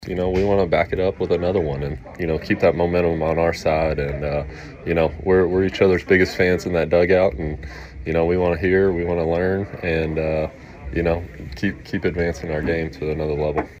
Royals winning pitcher Michael Wacha on getting successive quality starts